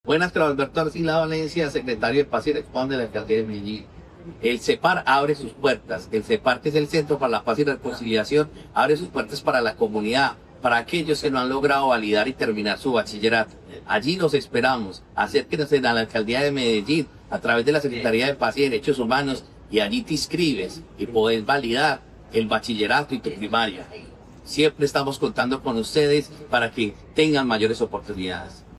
Declaraciones secretario de Paz y Derechos Humanos, Carlos Alberto Arcila Valencia La Alcaldía de Medellín, a través del Centro de Formación para la Paz y la Reconciliación -Cepar-, tiene abiertas las matrículas para su programa educativo.
Declaraciones-secretario-de-Paz-y-Derechos-Humanos-Carlos-Alberto-Arcila-Valencia.mp3